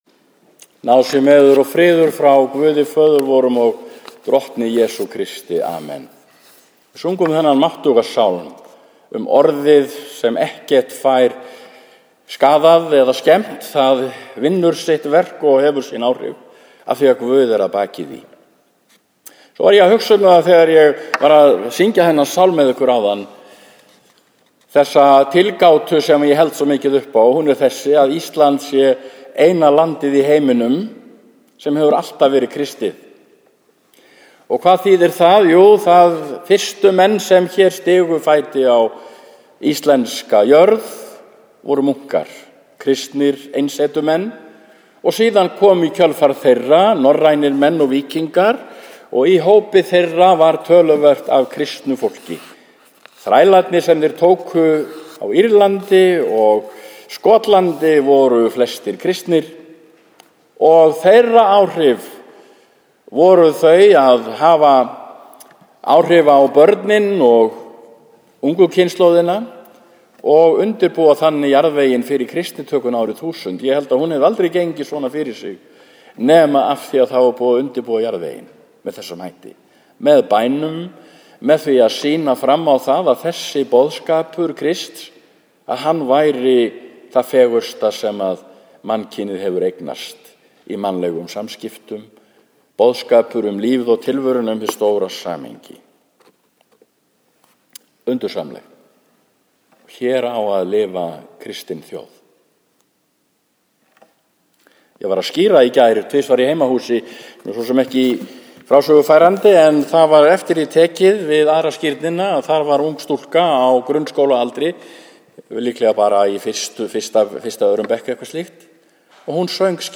Messa í Neskirkju sunnudaginn 10. nóvember 2013 kl. 11 sem var 24. sd. e. þrenningarhátíð og kristniboðsdagurinn.
Prédikunin var flutt út frá punktunum hér að neðan en þeim þó ekki fylgt nema að hluta til.